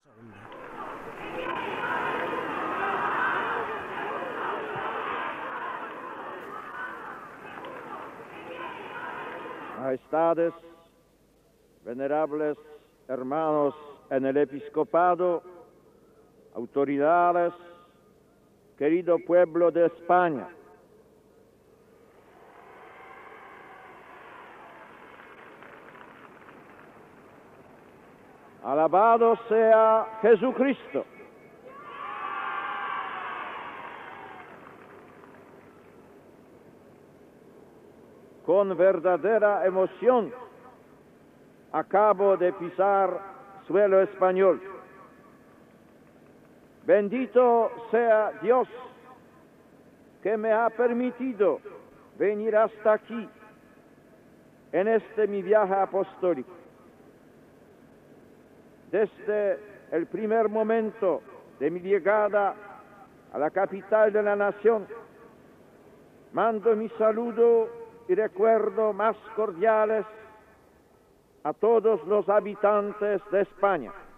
Homilía del sant pare Joan Pau II en l'acte amb els joves a l 'Estadio Santiago Bernabéu de Madrid en la seva primera visita a Espanya.
Paraules del sant pare Joan Pau II als joves que estaven fora de l 'Estadio Santiago Bernabéu de Madrid